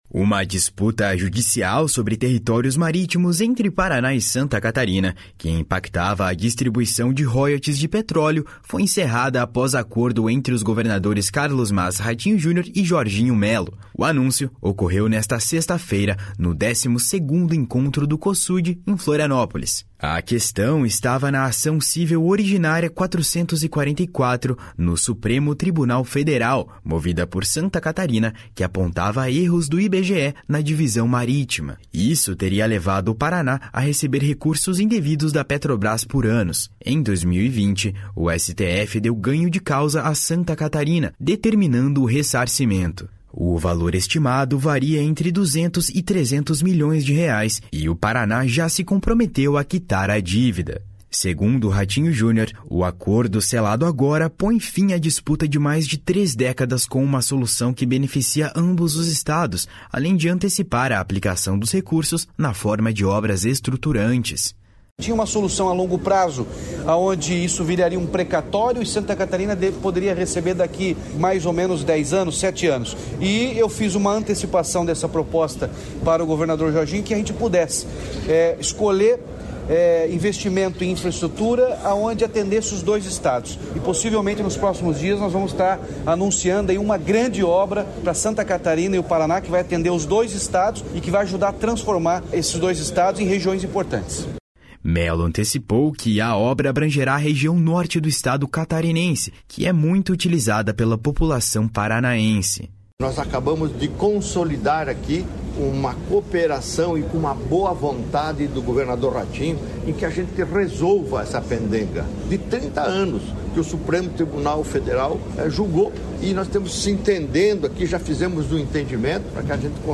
// SONORA RATINHO JUNIOR //
// SONORA JORGINHO MELLO //